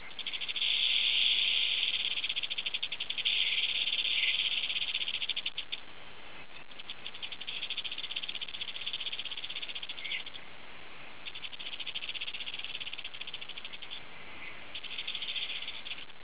Click on the picture for the sound of the hammocks
rattler1.wav